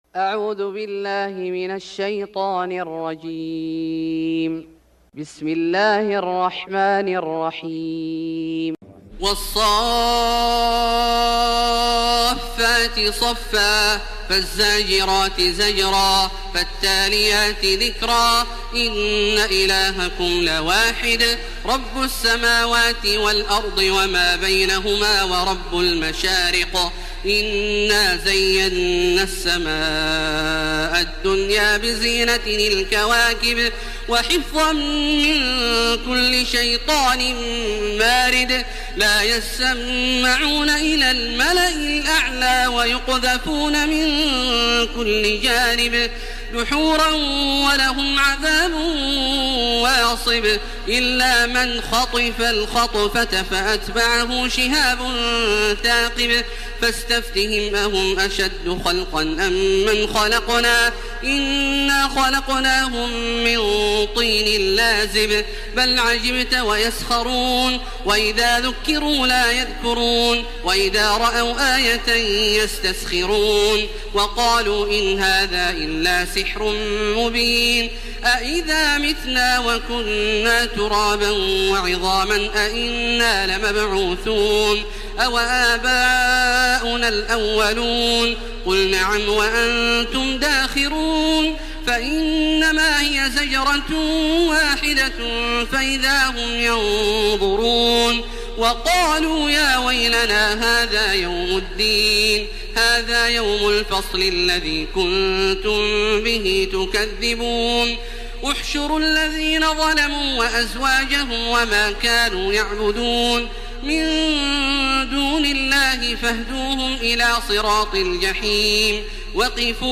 سورة الصافات Surat As-Saffat > مصحف الشيخ عبدالله الجهني من الحرم المكي > المصحف - تلاوات الحرمين